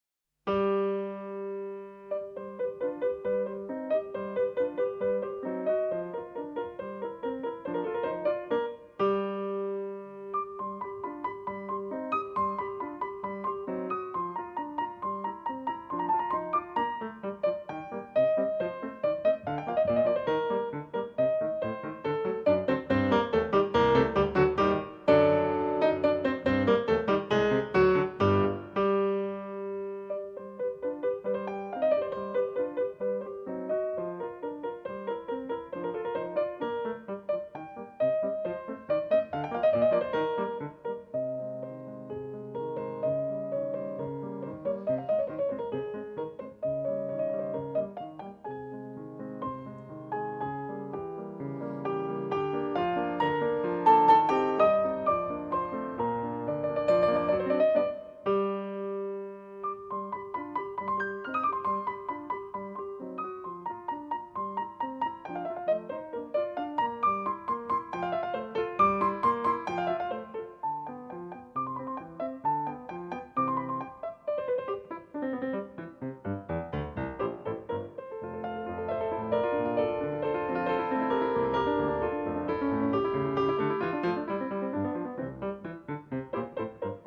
piano
As-dur Allegretto.mp3